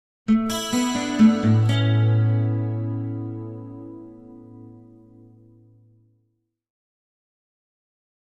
Acoustic Guitar - Music Stinger - Picked With Chorus 2